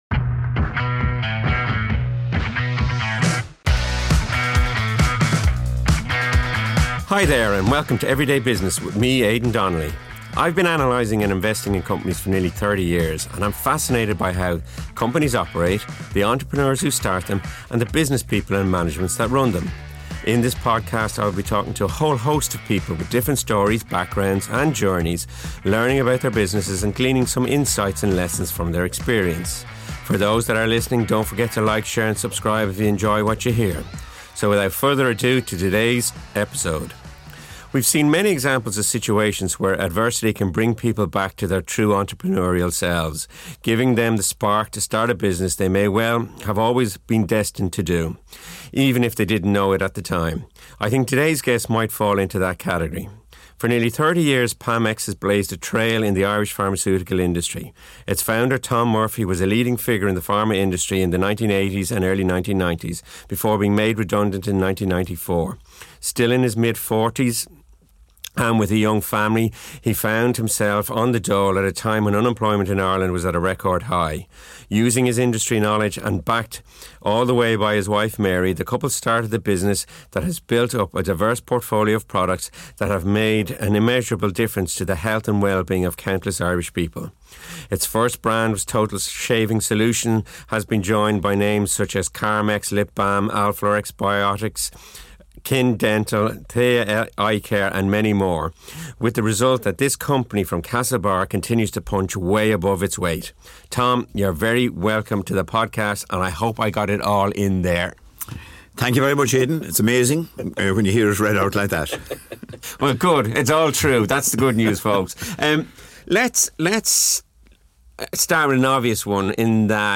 This podcast brings you insightful conversation